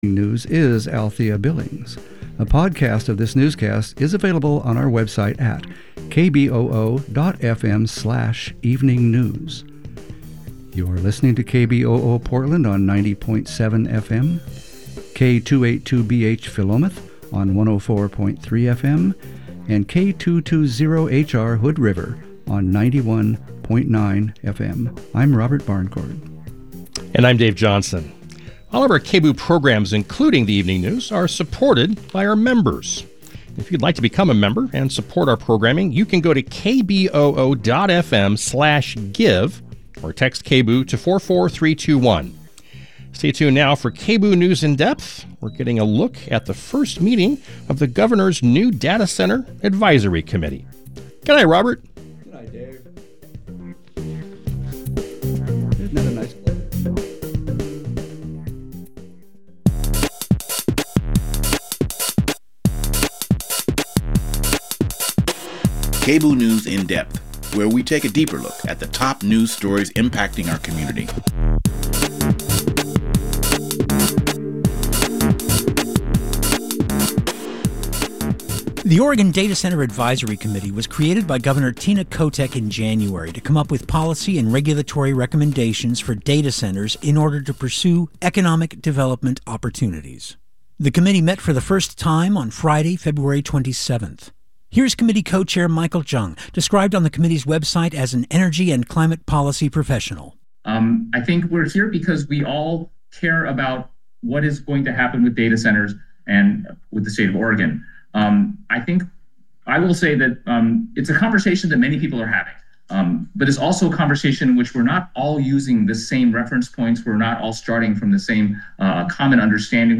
Hosted by: KBOO News Team